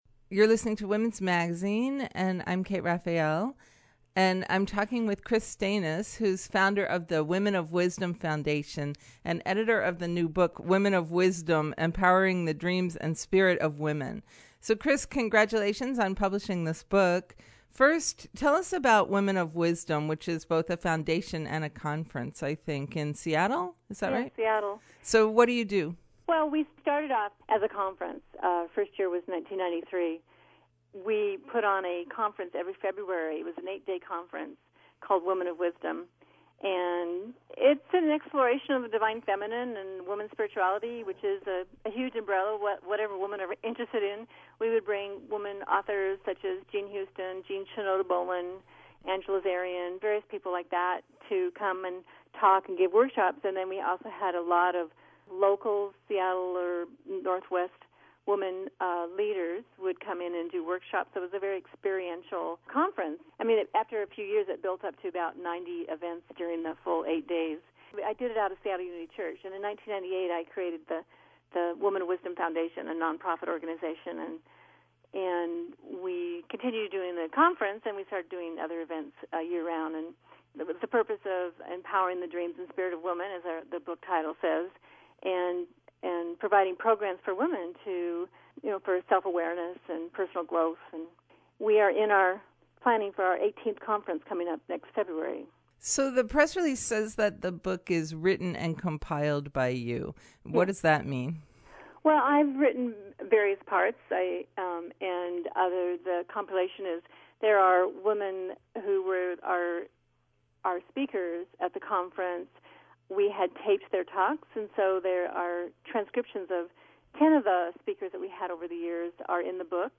Women of Wisdom: an Interview